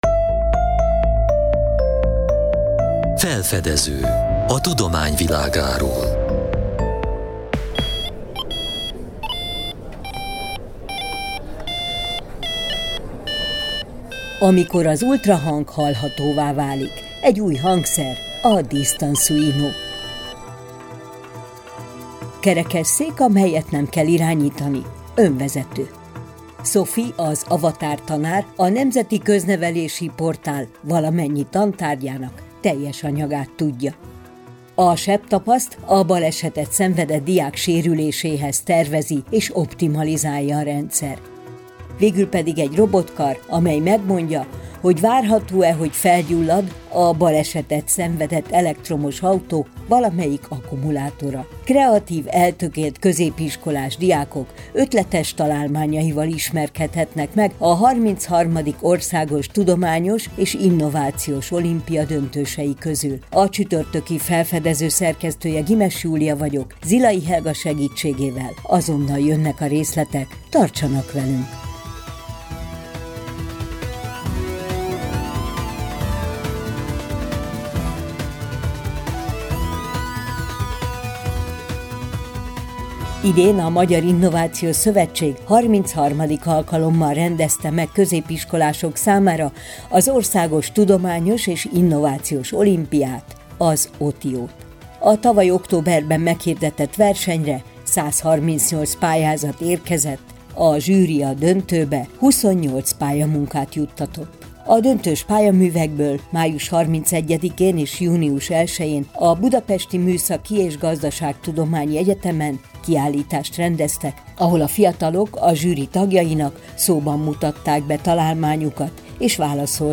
Höre dich das Interview mit uns in der Sendung Felfedező von Kossuth Radio an!